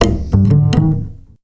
Upright 2 F.wav